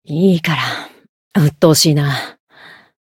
灵魂潮汐-迦瓦娜-互动-不耐烦的反馈2.ogg